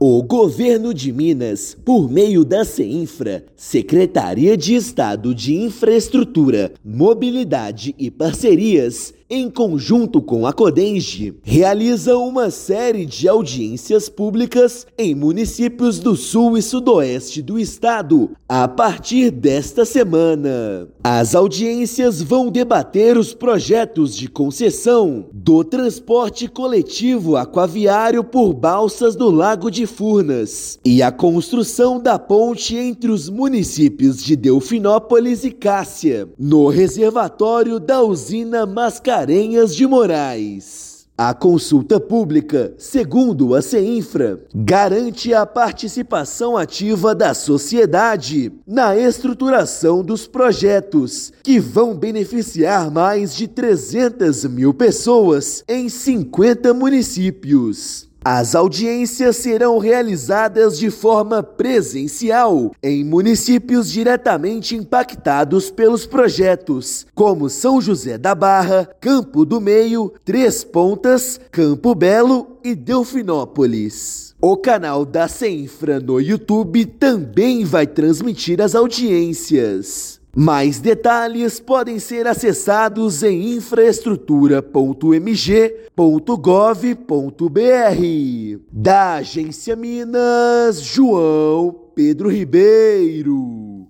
Encontros serão realizados em outubro para debater concessão de balsas e construção da ponte entre Cássia e Delfinópolis. Ouça matéria de rádio.